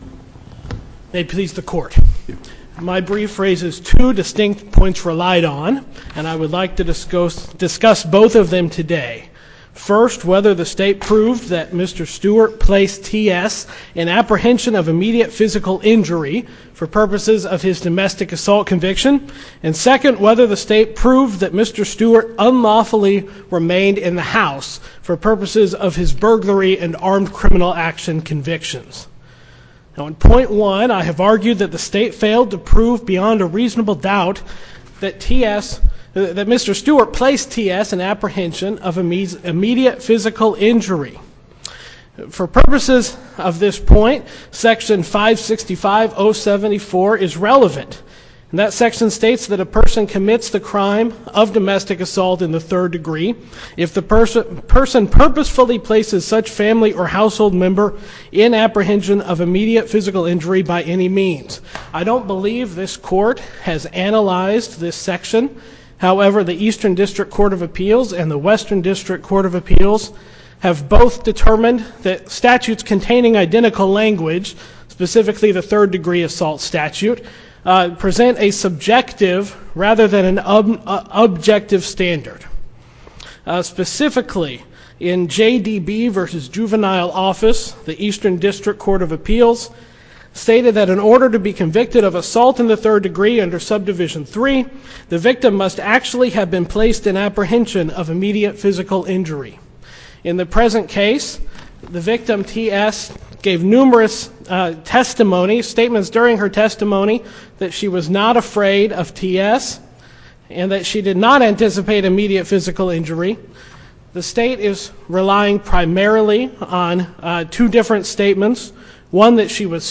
link to MP3 audio file of oral arguments in SC97198